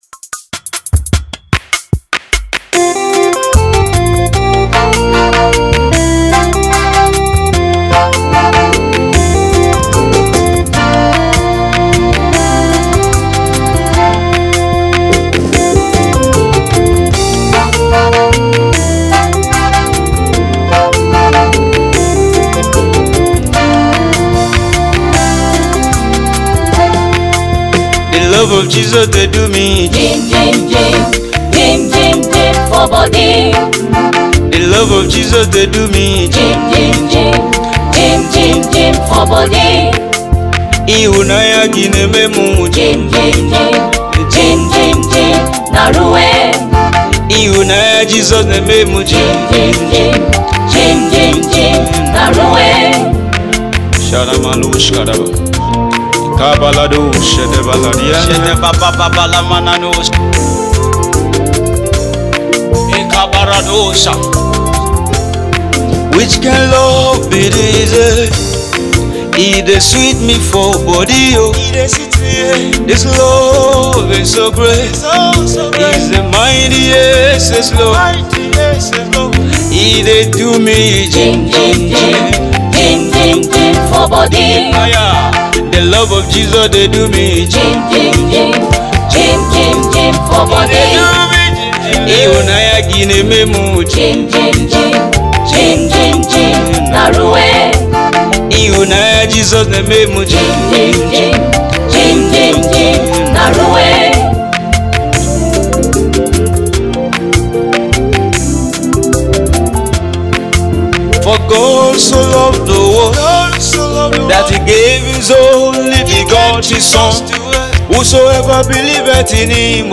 soul-stirring melodies
heartfelt anthem
compelling and uplifting